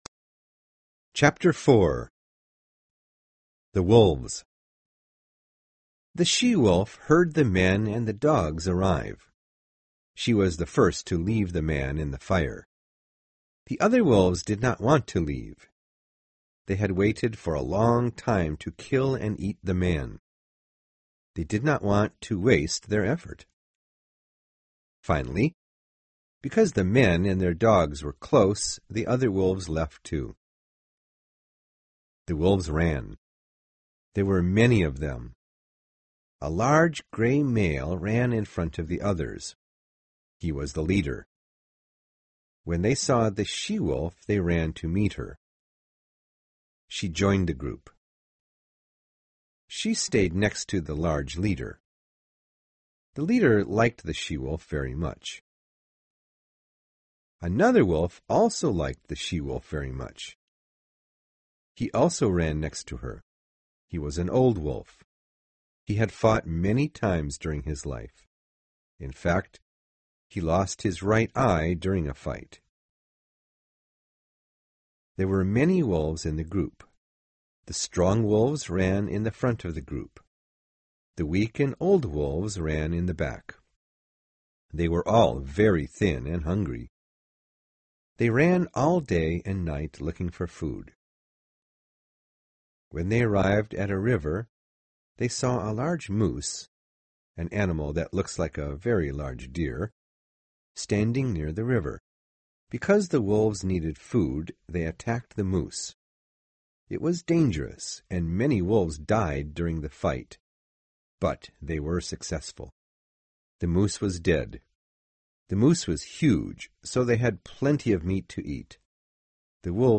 有声名著之白牙 04 听力文件下载—在线英语听力室